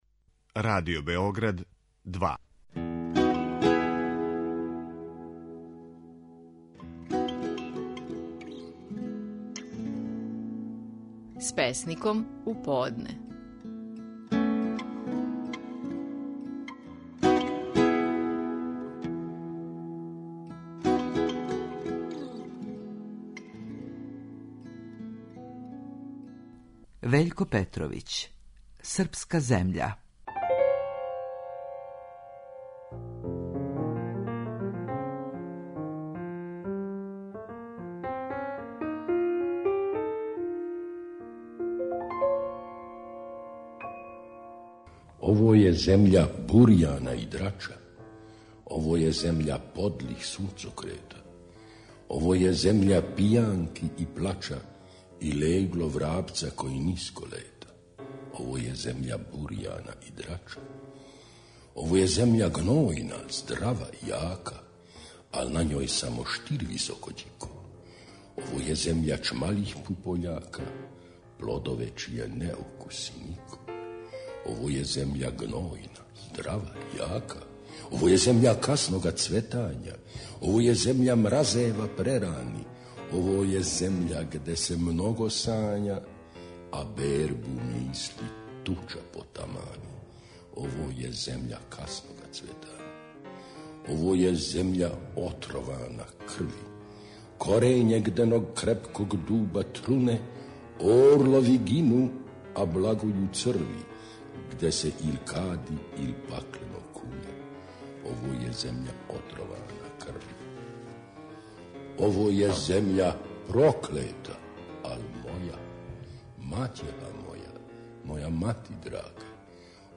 Стихови наших најпознатијих песника, у интерпретацији аутора.
Вељко Петровић говори своју песму „Српска земља".